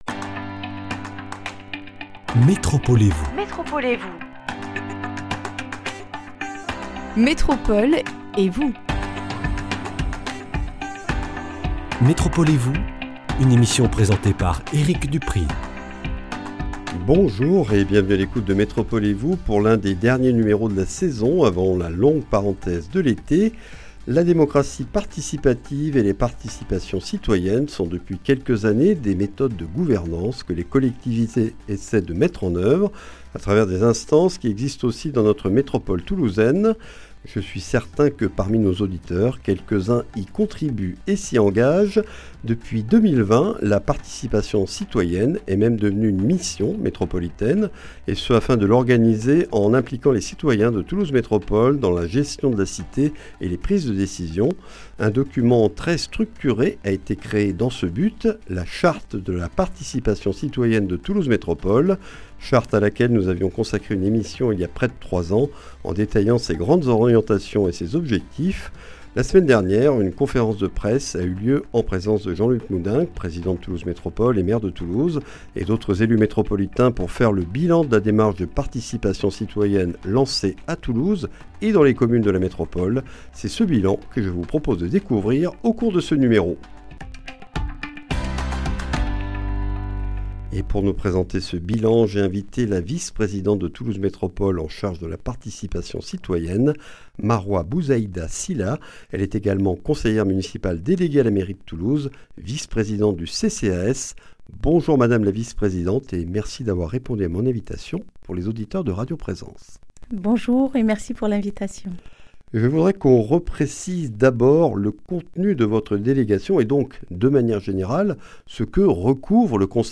Une émission avec Maroua Bouzaida-Sylla, conseillère municipale déléguée à la mairie de Toulouse, vice-présidente de Toulouse Métropole chargée de la Participation citoyenne. Elle nous présente les objectifs, les grandes orientations et les instances de la participation citoyenne dans la métropole toulousaine, et le bilan que l’on peut faire de cette démarche à fin 2024.